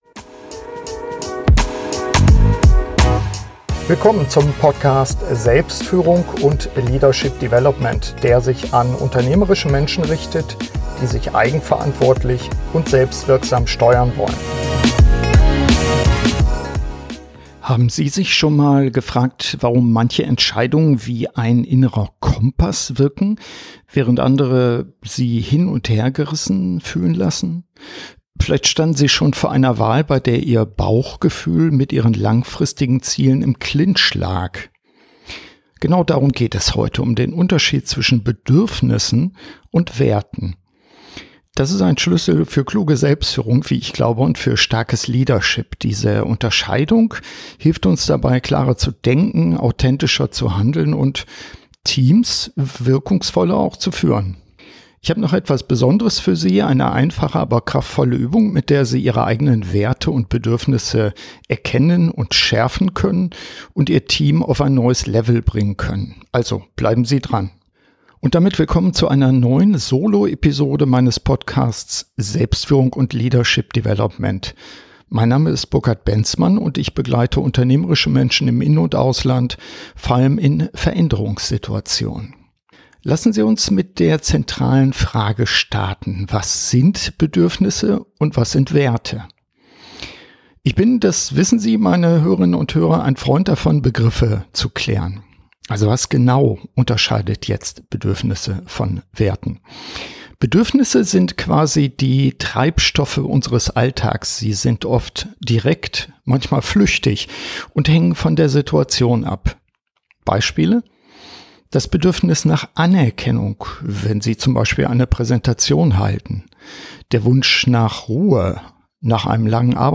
Beschreibung vor 11 Monaten In dieser Solo-Episode geht es um einen Schlüssel zur Selbstführung: den Unterschied zwischen Bedürfnissen und Werten. Sie erfahren, warum diese Unterscheidung essenziell für wirksame Entscheidungen ist – im Alltag wie in der Führung. Zusätzlich stelle ich Ihnen eine einfache Übung vor, mit der Sie Ihr Team stärken können.